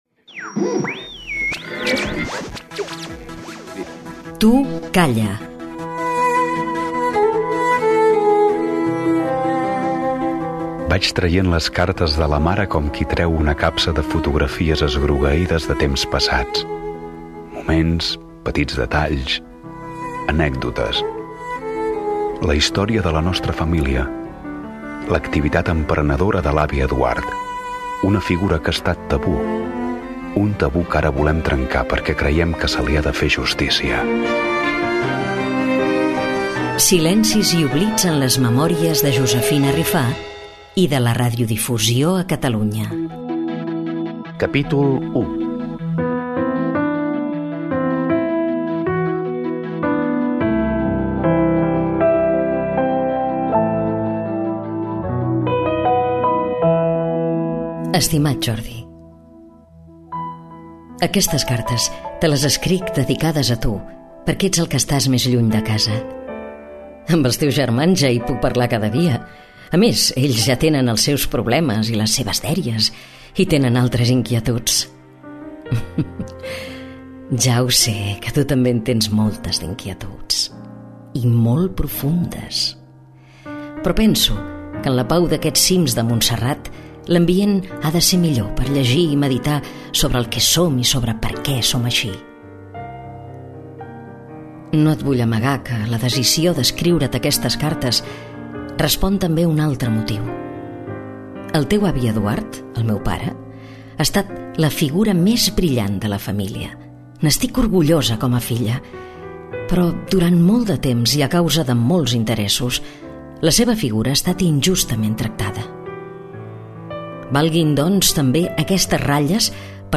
Divulgació
Presentador/a